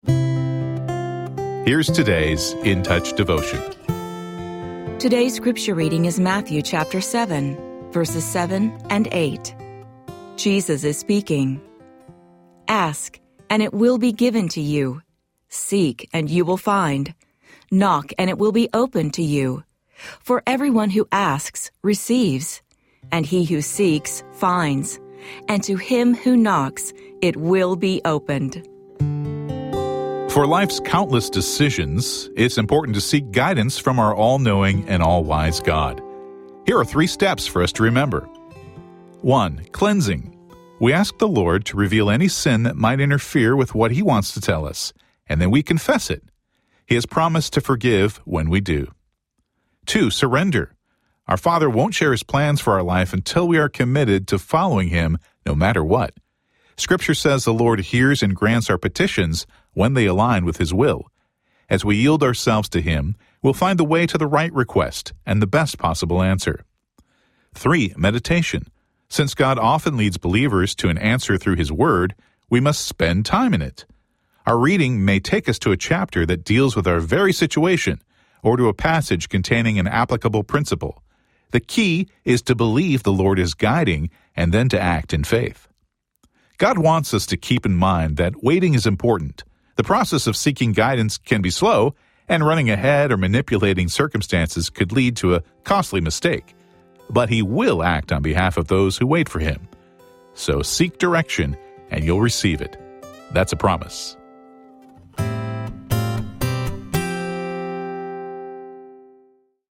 Daily audio devotional